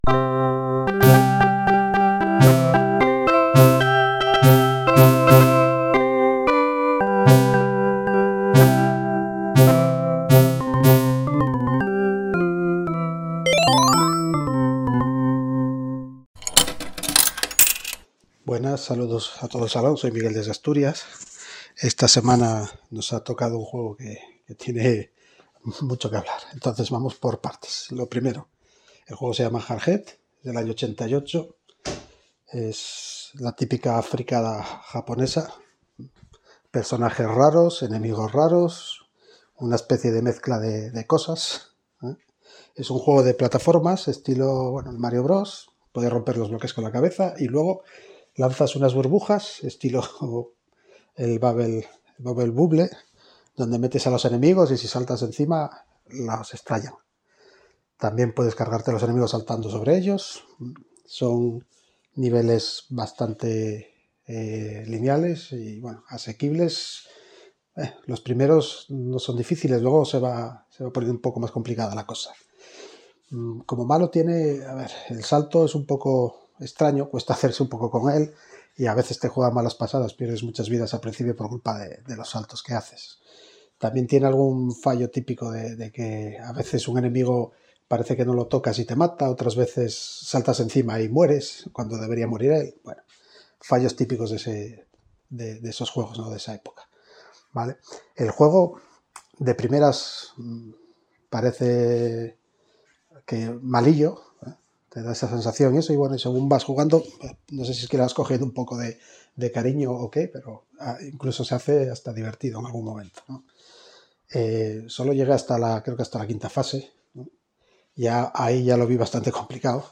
Lo único cierto es que la duración de estos podcasts depende directamente de la cantidad de audios recibidos, y este capítulo ha sido, con mucho, el más largo hasta la fecha.
Si buena o mala… tendréis que escuchar a nuestros socios para saberlo.